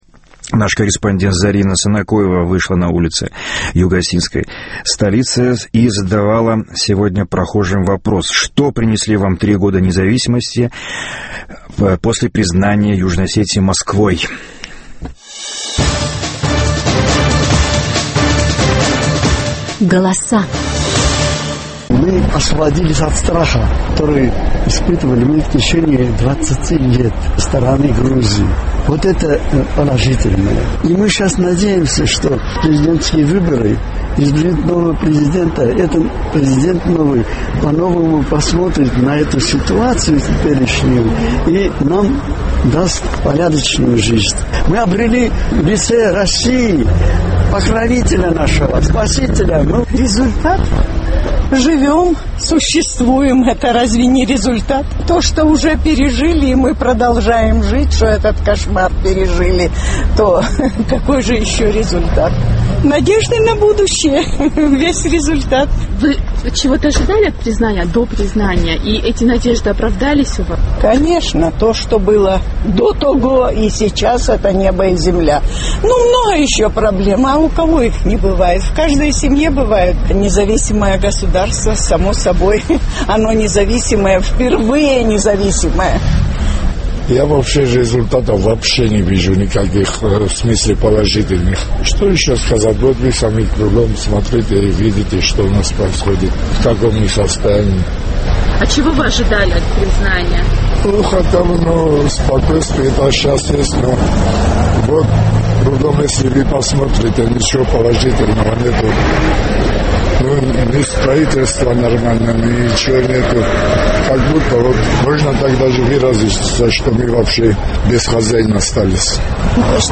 Голоса